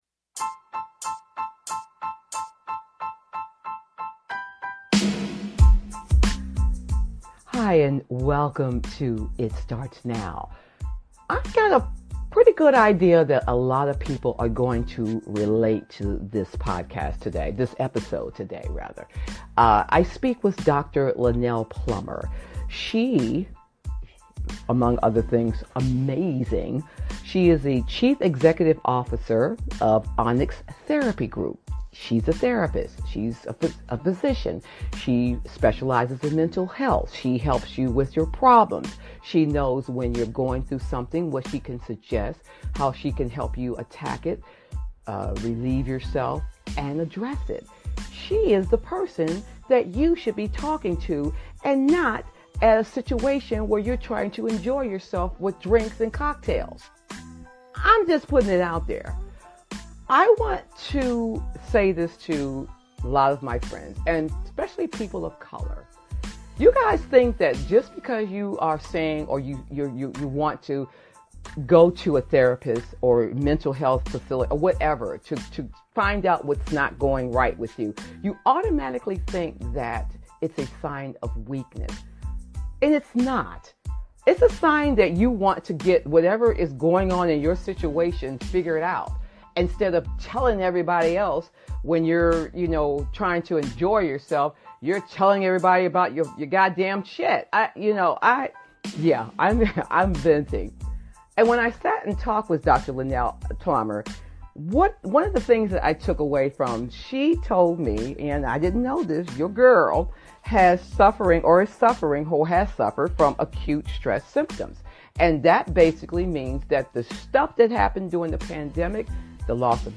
Talk Show Episode, Audio Podcast, It Starts Now and It's Mental And There's Nothing Wrong With That on , show guests , about It's Mental And There's Nothing Wrong With That, categorized as Design,Beauty,Fashion,Business,Entertainment,Health & Lifestyle,Love & Relationships,Philosophy,Society and Culture